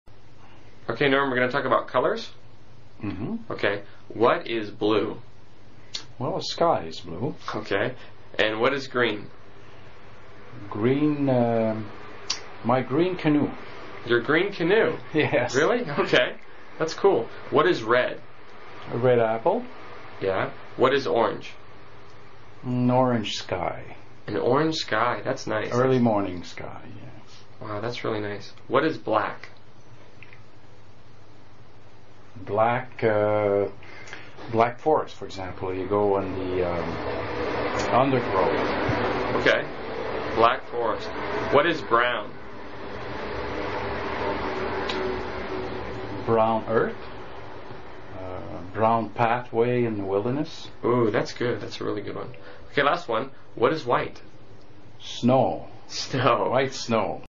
实战口语情景对话 第185期:颜色 Colors